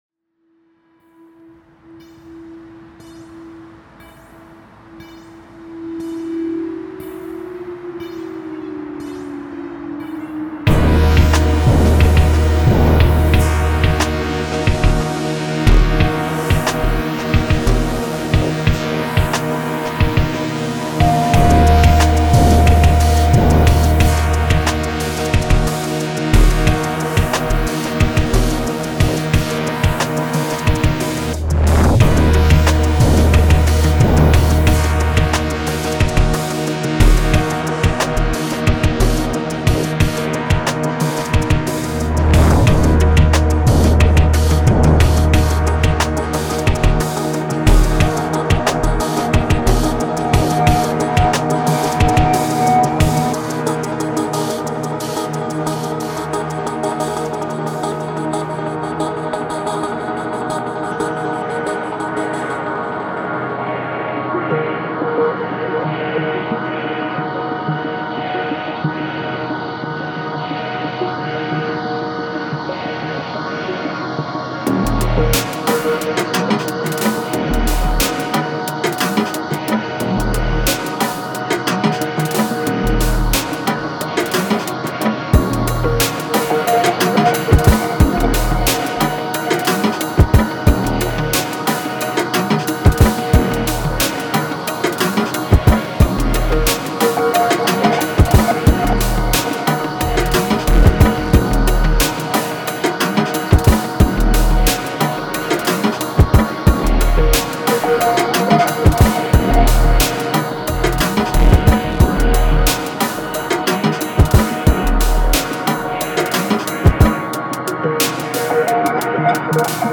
Genre:Cinematic
この綿密に作り込まれたループコレクションは、緊張感、神秘性、そしてシネマティックな暗さの本質を捉えています。
23 Bass Loops
15 Synth Loops
33 Pad & Drone Loops
09 Piano Loops
05 Processed Vocal Loops